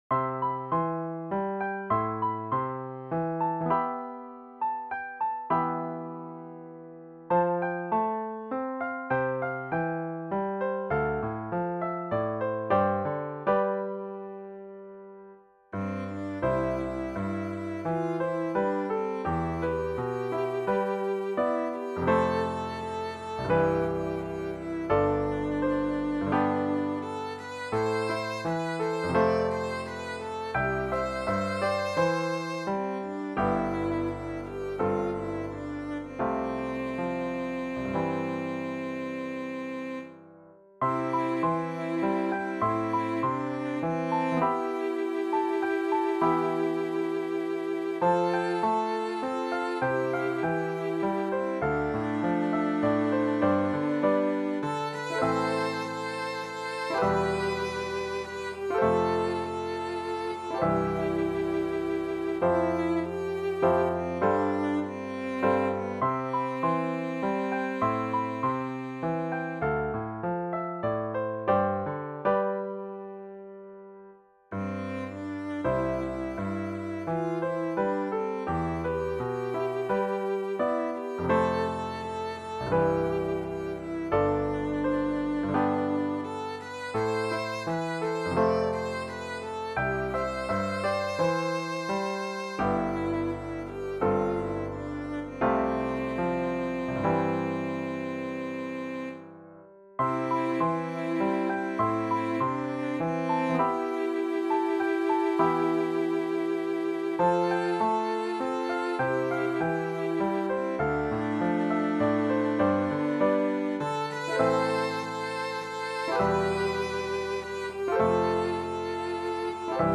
(*Vocal duet, piano)
Here is a computer generated mp3 recording of the *duet.